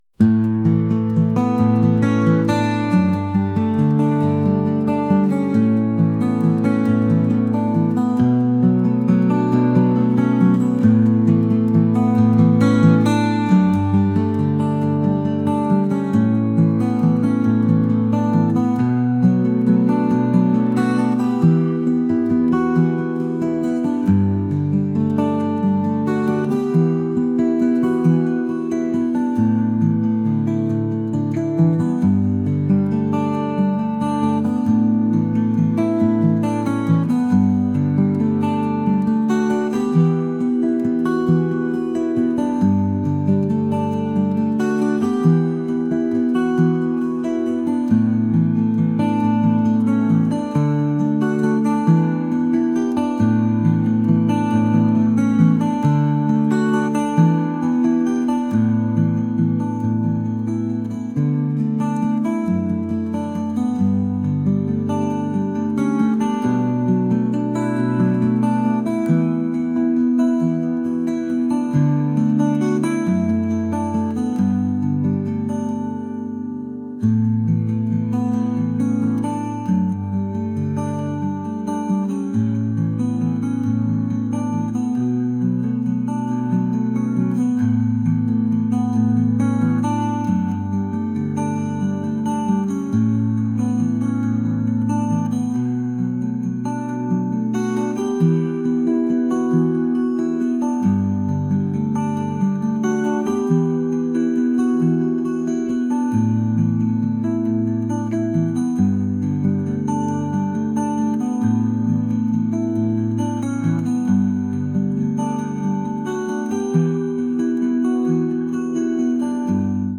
acoustic | folk | indie